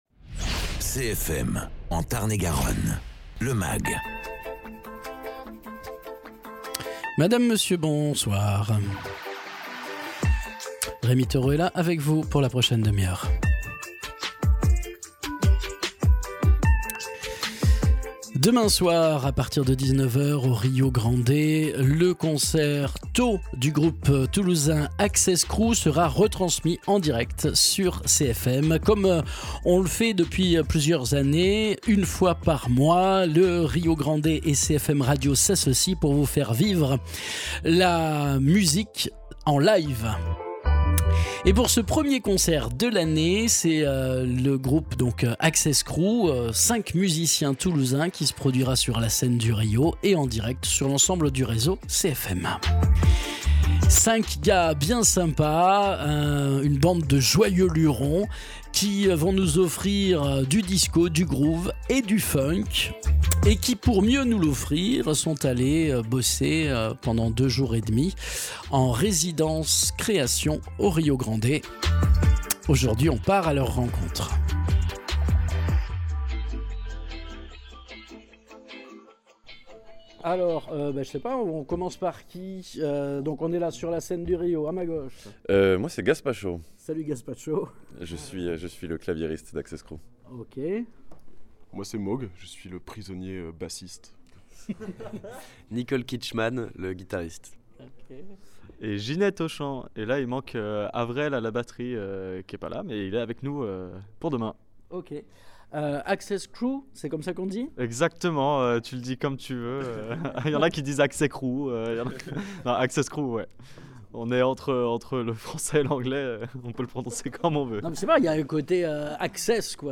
Rencontre sous le signe de la bonne humeur et de l’humour avec les musiciens toulousains d’Access Crew qui joueront ce jeudi sur la scène du RIO pour un "concertôt" retransmis en direct sur CFM